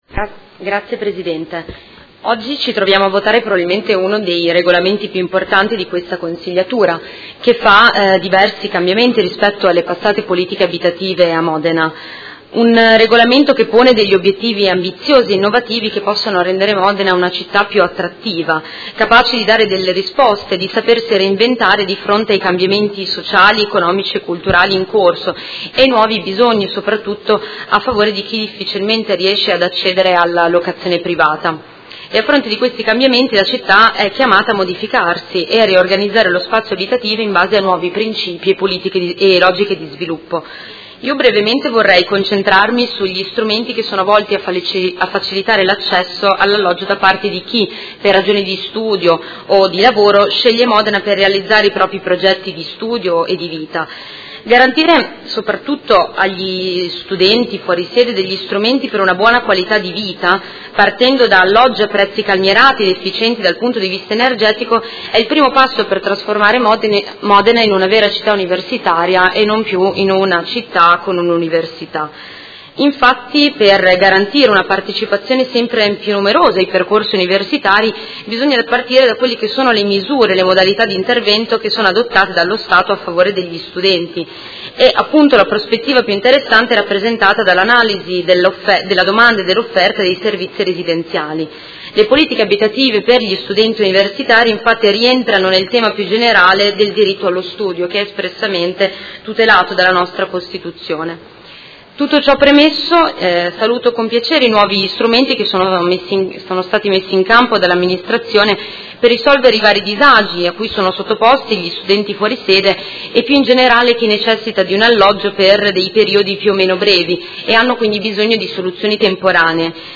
Seduta del 17/05/2018. Dibattito su proposta di deliberazione: Regolamento Edilizia convenzionata e agevolata - Approvazione, emendamenti e Ordine del Giorno presentato dal Gruppo Consigliare PD avente per oggetto: Rafforzamento delle politiche pubbliche per l'accesso alla casa attraverso il nuovo regolamento per l'edilizia convenzionata e agevolata